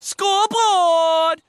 score board
score-board.mp3